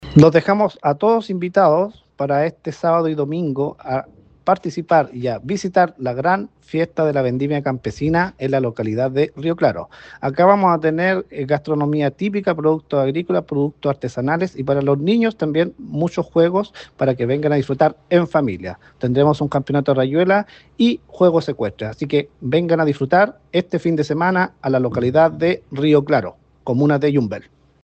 El alcalde de Yumbel, José Sáez, destacó el carácter comunitario de esta celebración e invitó a la comunidad a ser parte de esta fiesta.